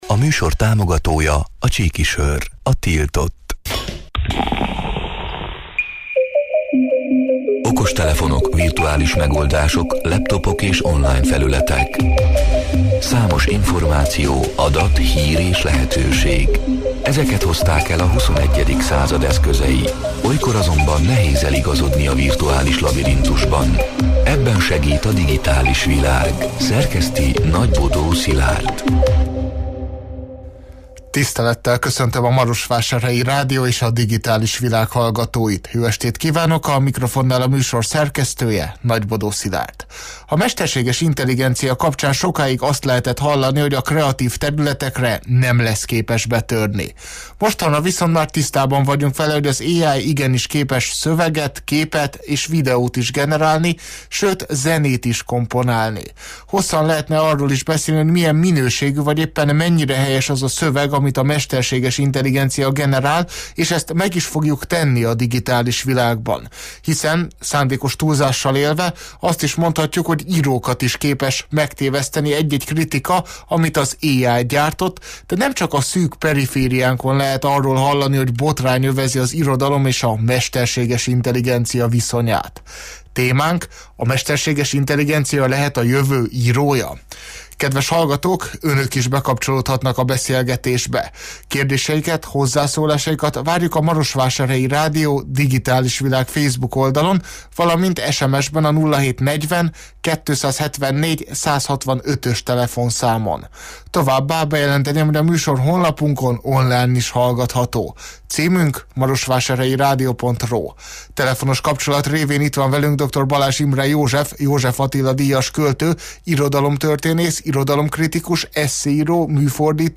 A Marosvásárhelyi Rádió Digitális Világ (elhangzott: 2025. január 28-án, kedden este nyolc órától élőben) c. műsorának hanganyaga: